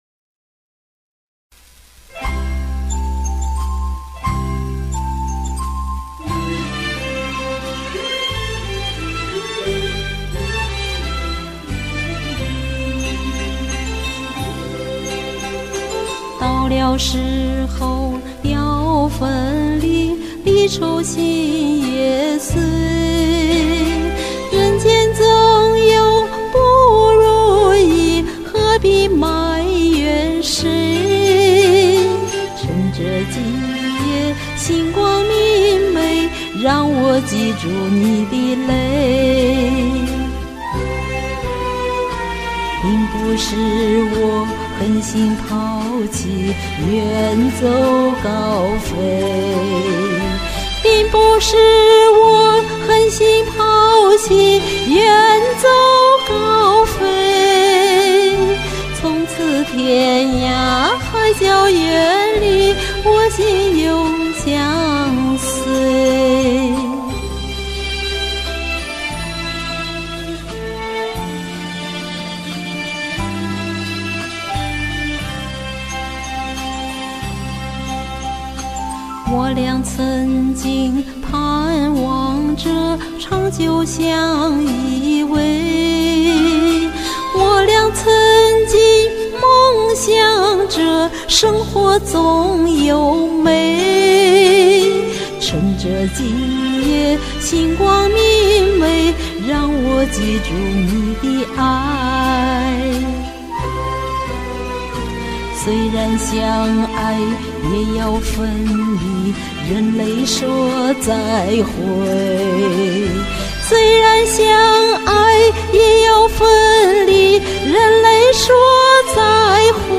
我在一堆的干声中找呀找，终于找到这首歌，但干声已和伴奏混合。
这次为了听众的耳朵，也加点混响，我再发一次。
中音音色美